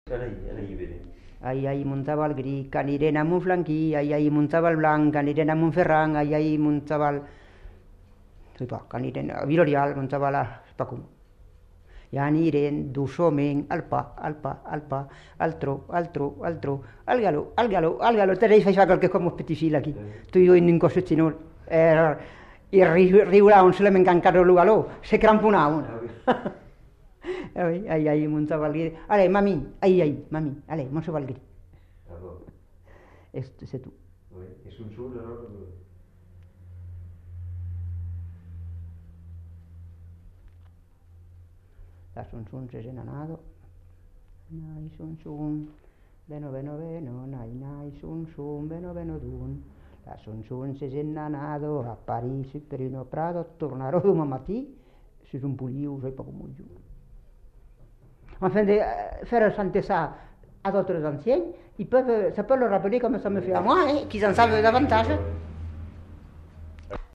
Lieu : Castillonnès
Genre : forme brève
Type de voix : voix de femme
Production du son : récité
Classification : formulette enfantine